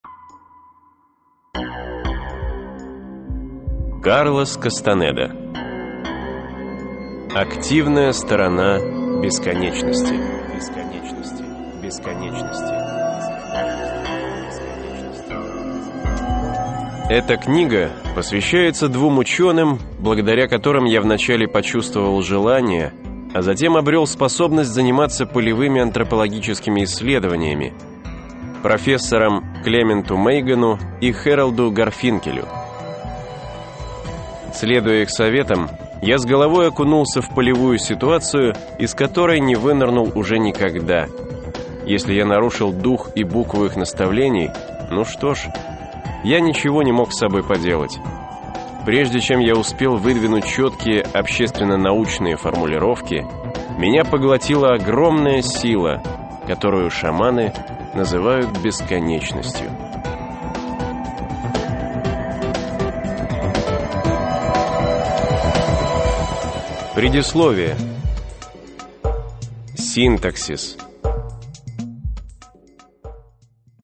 Аудиокнига Активная сторона бесконечности | Библиотека аудиокниг